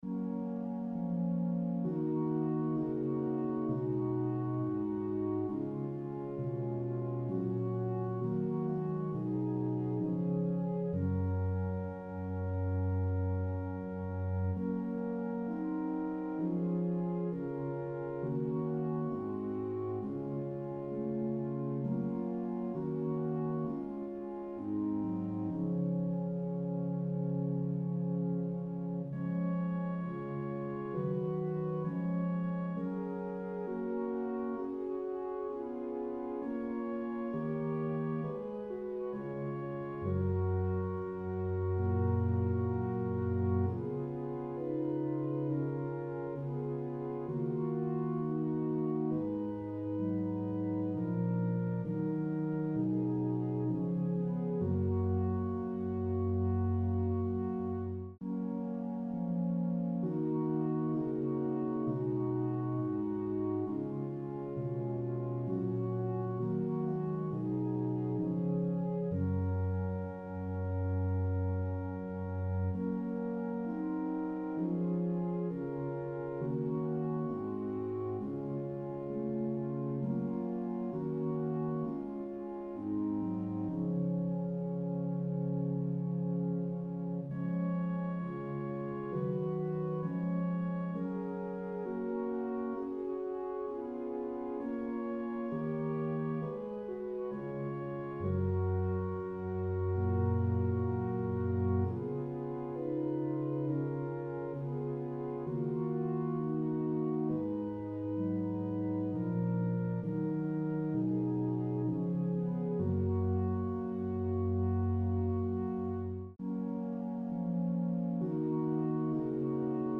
Click the Button to sing the prayer in F#, or play the song in a New Window
Soul-of-My-Savior-F-Sharp.mp3